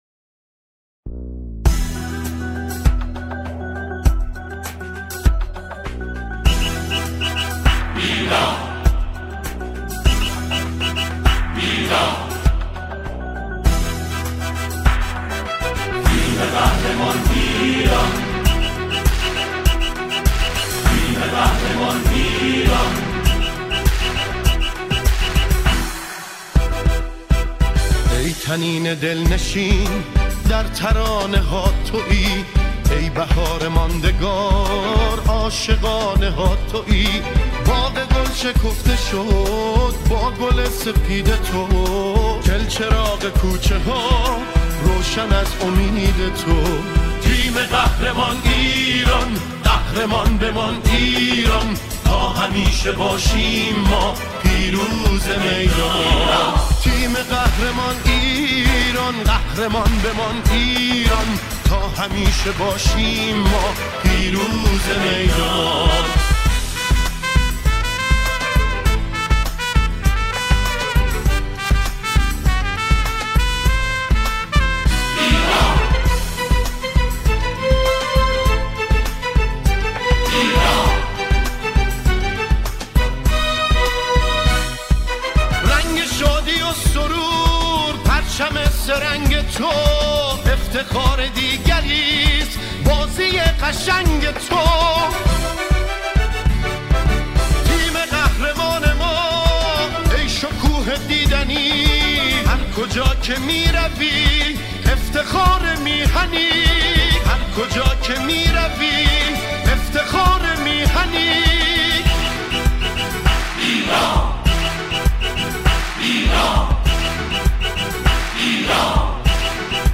سرودهای ورزشی